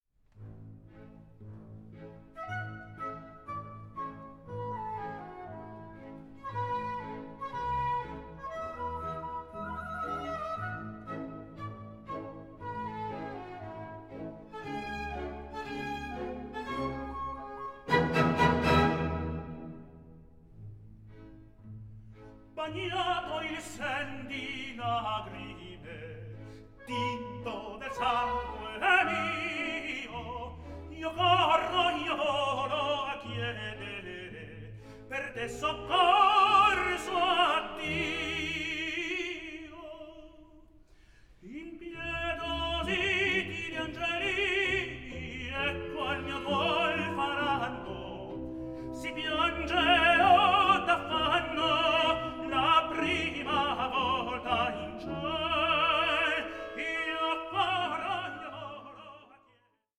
TENOR ARIAS
Star tenor
period instrument playing